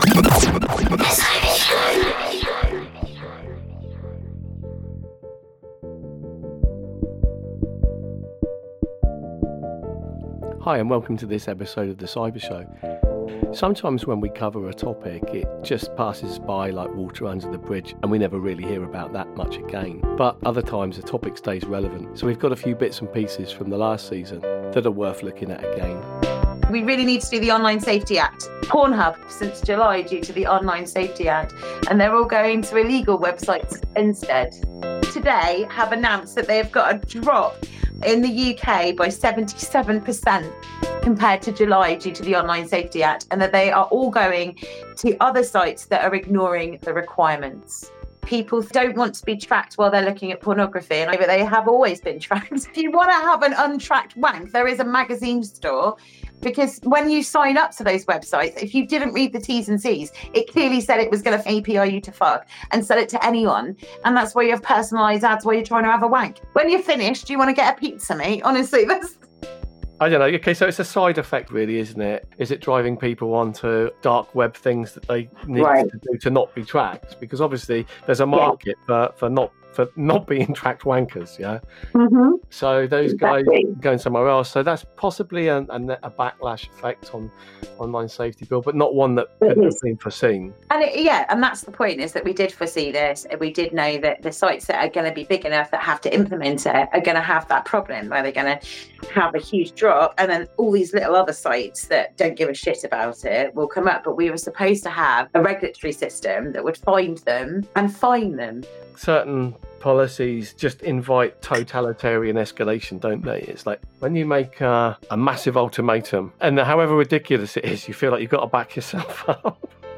How do we join the dots of ecology, sustainability, privacy and democratic control of tech? What are the forces shaping the Internet today? (additional music